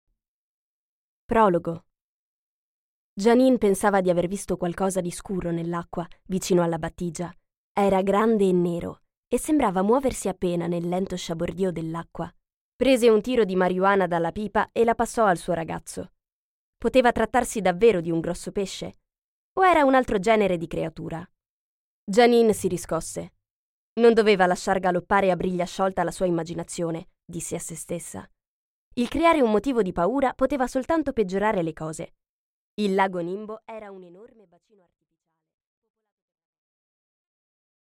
Аудиокнига Oscurita’ Perversa | Библиотека аудиокниг
Прослушать и бесплатно скачать фрагмент аудиокниги